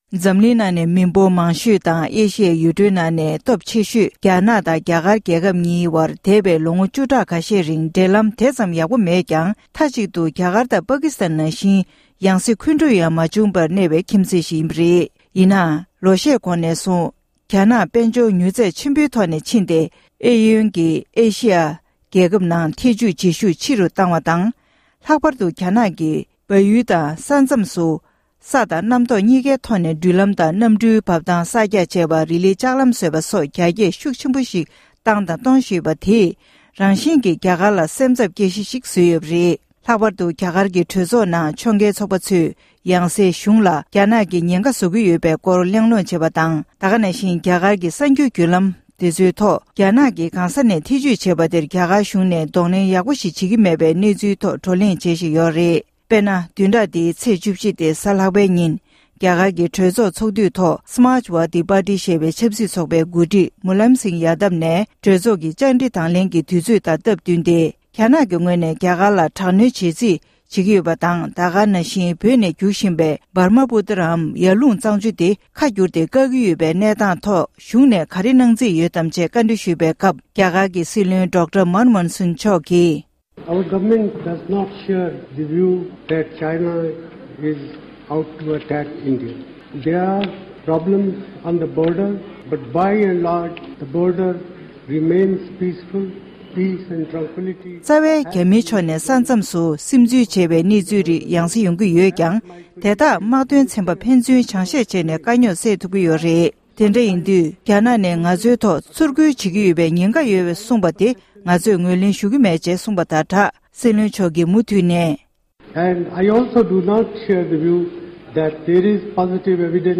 རྒྱ་གར་གྱི་སྲིད་བློན་མཆོག་གིས་རྒྱ་དཀར་ནག་གཉིས་ཀྱི་འབྲེལ་བའི་སྐོར་གྲོས་ཚོགས་ནང་གསུང་བཤད་གནང་བ།
སྒྲ་ལྡན་གསར་འགྱུར། སྒྲ་ཕབ་ལེན།